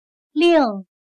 /lìng/Orden